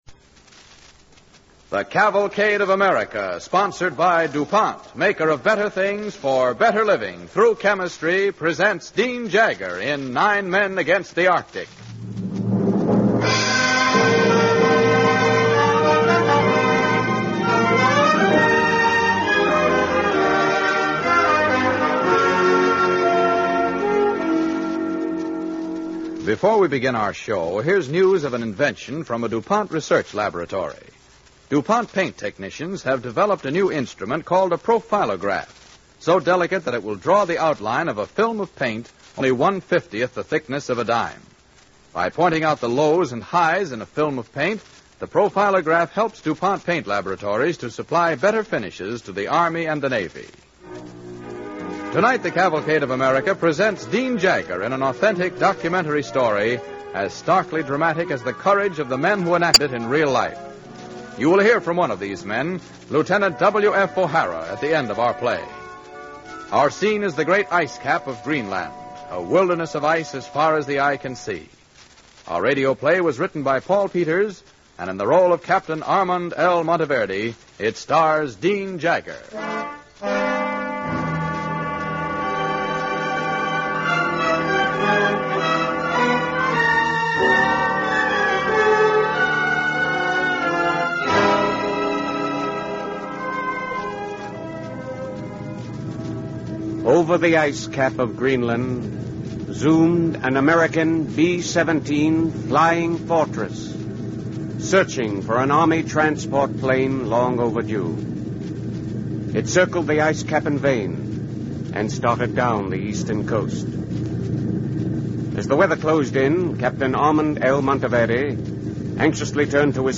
A RADIO PLAY OF THESE SAME 9 AIRMEN DOWNED ON GREENLAND FOR 129 DAYS